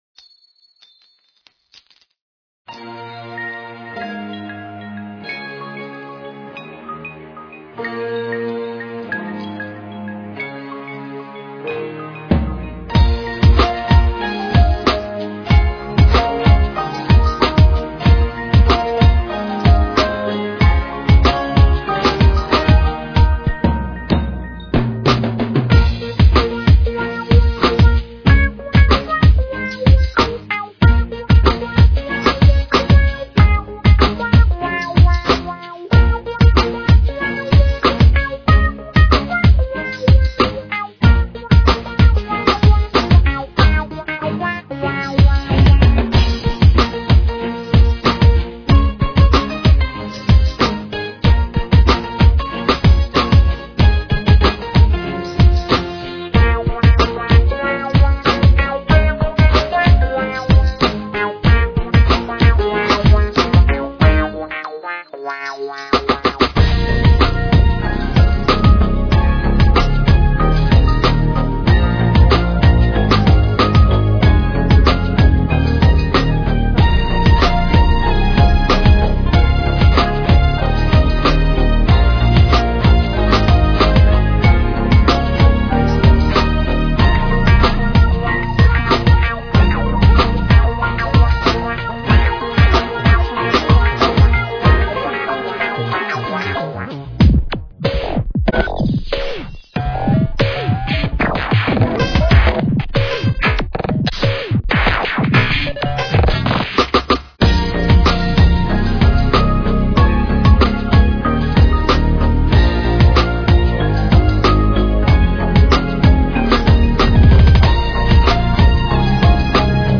Modern Dance.